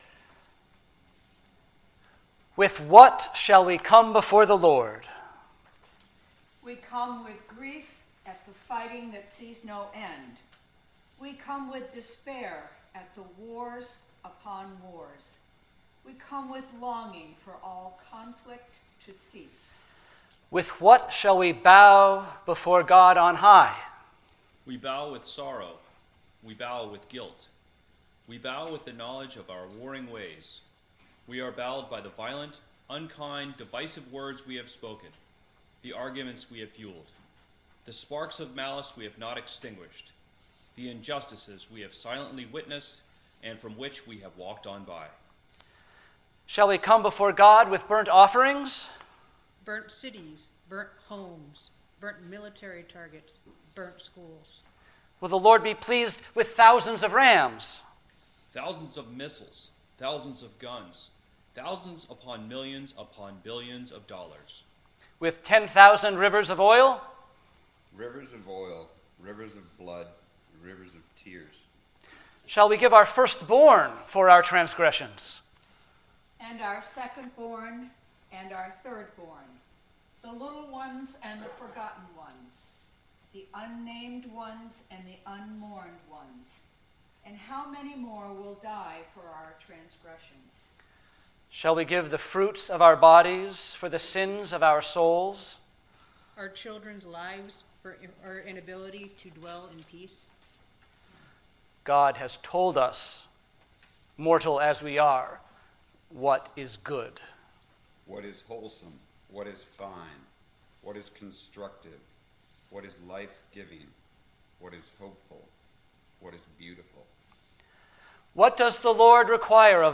Sermons With What Shall I Come Before the Lord?